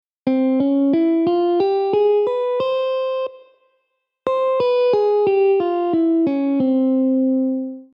1-b2-3-4-5-b6-7
(C-Db-E-F-G-Ab-B-C)